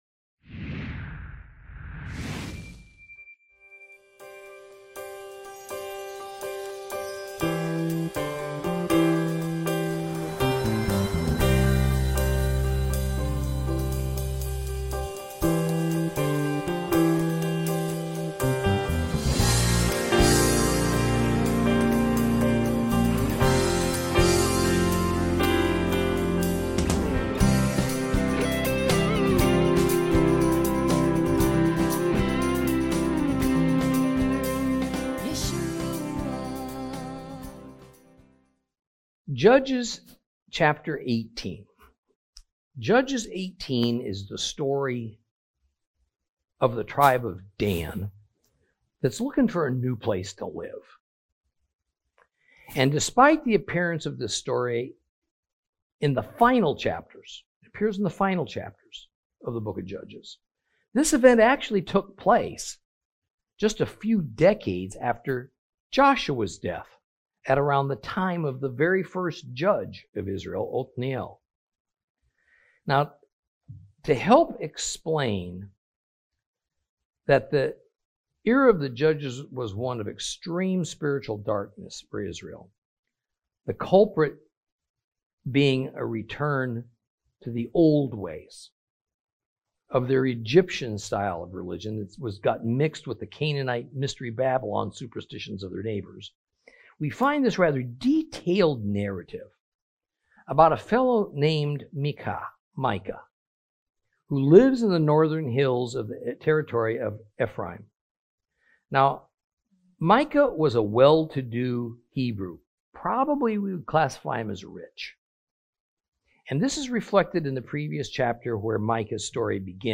Lesson 26 Ch18 Ch19 - Torah Class